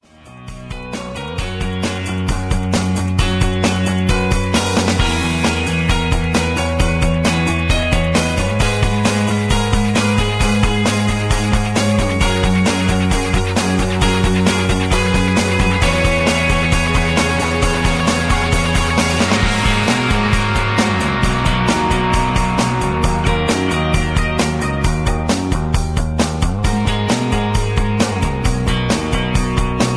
rock and roll, rock